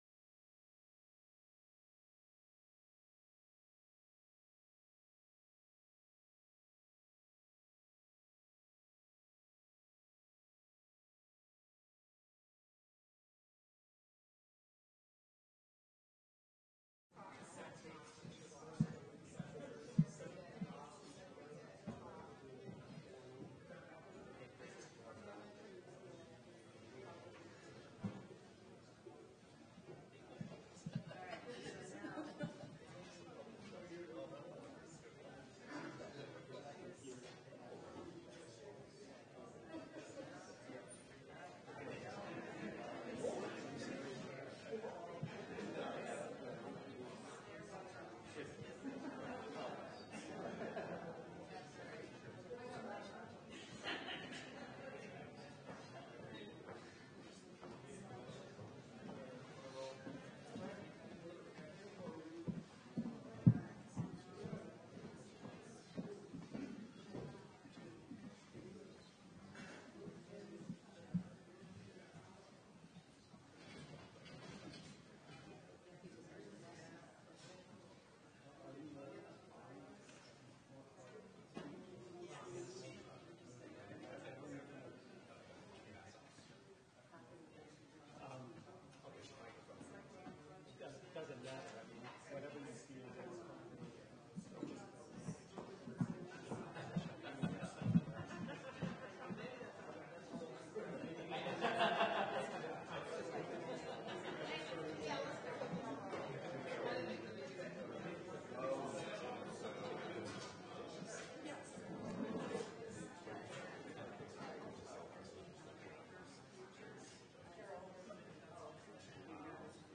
Agenda for Faculty Senate Meeting November 12, 2025 — 3:30-5:00PM Physical location: Schwartz Auditorium, Rockefeller Hall Contact your unit’s Faculty Senator for the zoom link.